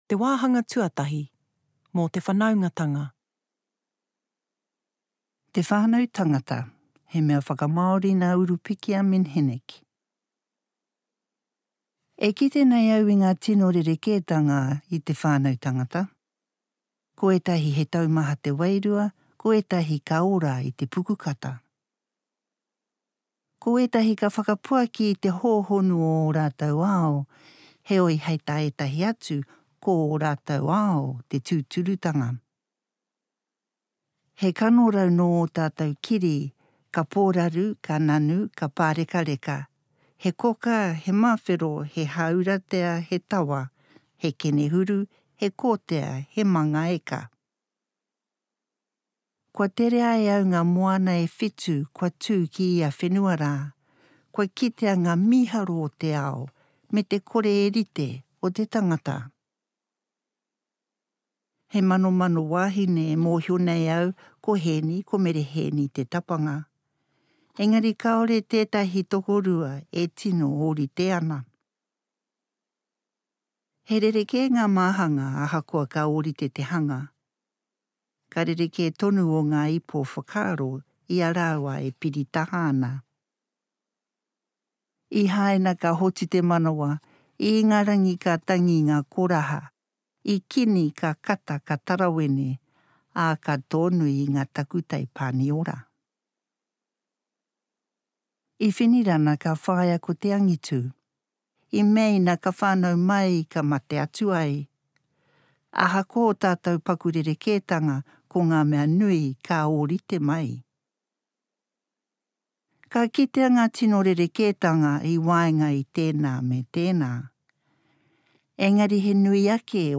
Format: Digital audiobook